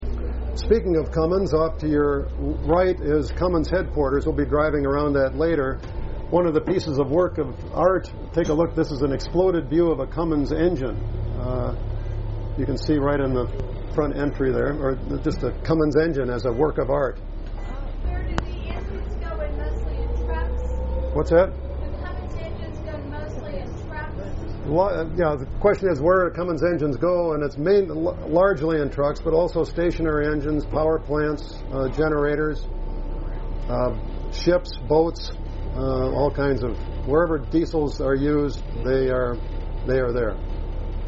Tour Guide